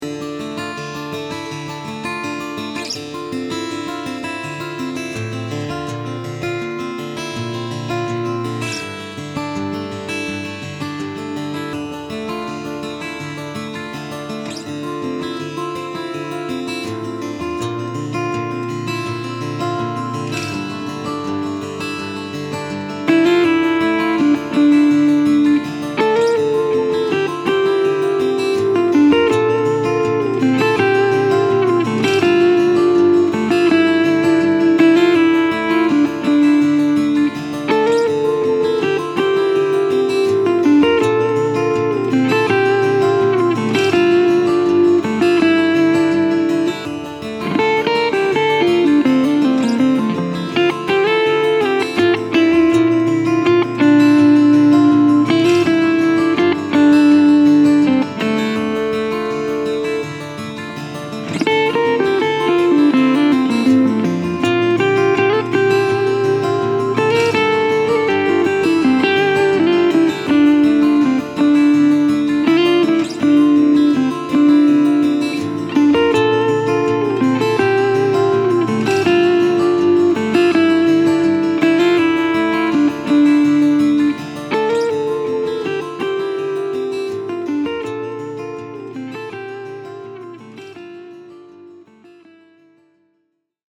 2.-TAS1C-Fingerpicking-Melody-Line.mp3